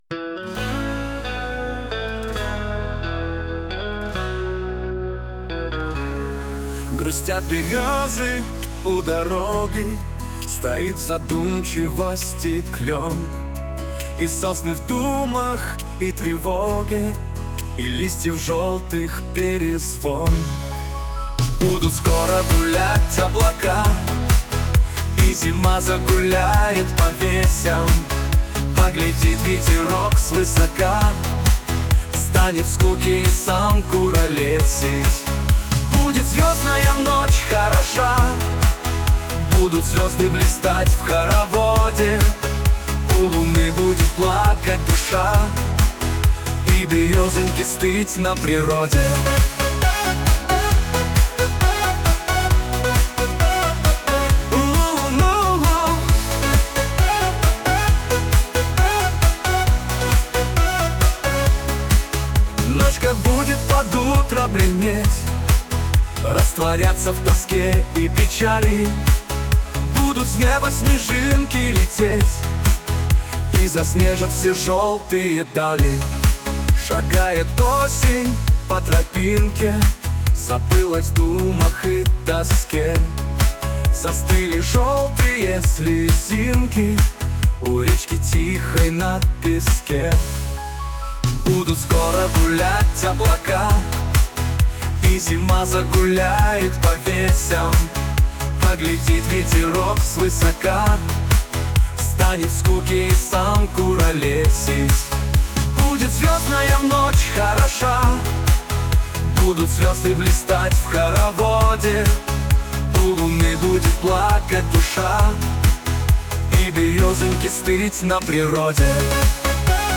песня сгенерирована автором в нейросети